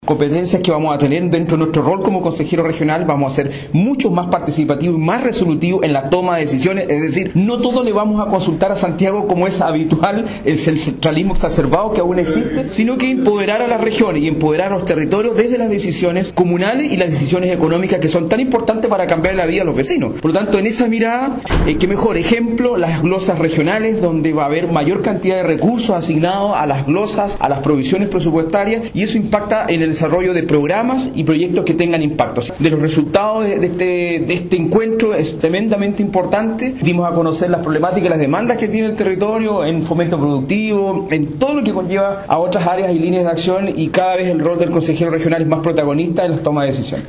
En tanto el Consejero Regional, Francisco Cárcamo, calificó el encuentro como positivo, apuntando a que los consejeros regionales deben tener un rol más activos en la definición de las nuevas competencias de los gobiernos regionales y que signifique además empoderar a las regiones y a los gobiernos comunales: